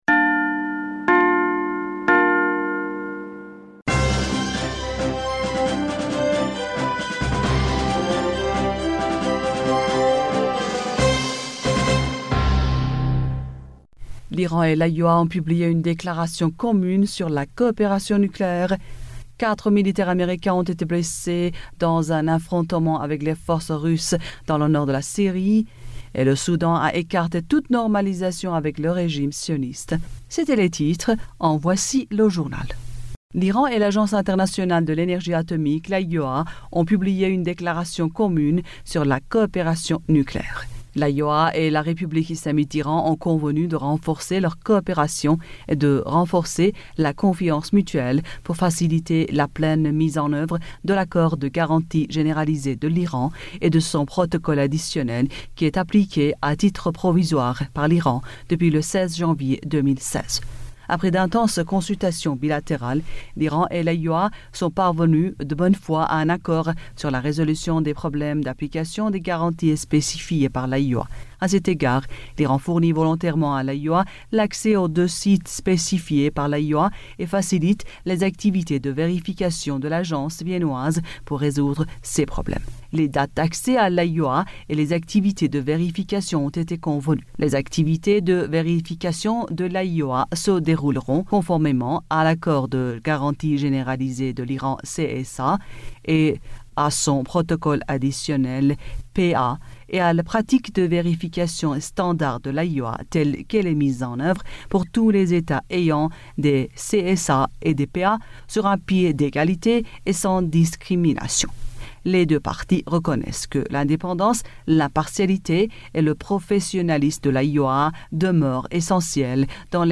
Bulletin d'information du 27 Aout 2020